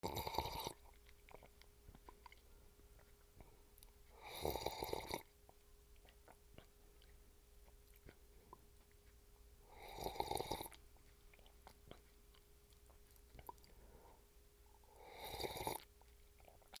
コーヒーをすする
『シュルル』